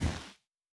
Land Step Snow A.wav